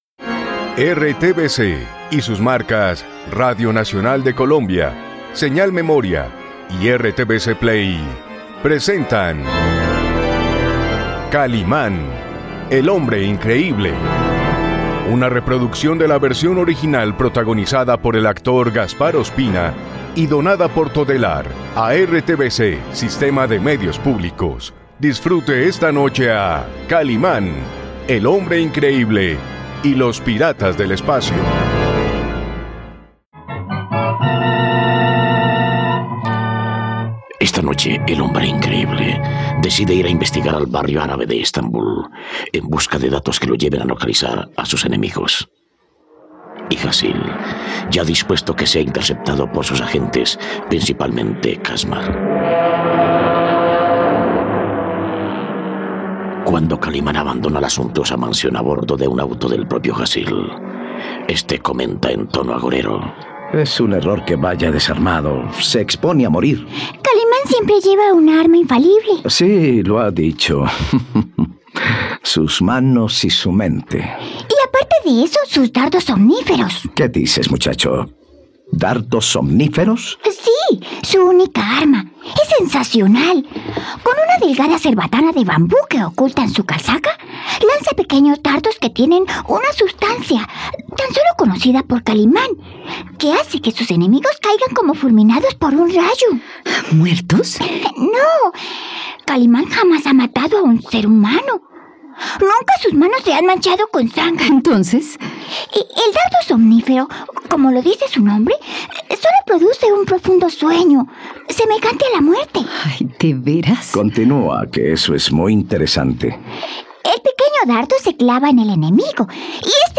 ..Disfruta de una nueva aventura junto al hombre increíble, en RTVCPlay puedes disfrutar de la radionovela completa de 'Kalimán y los piratas del espacio'.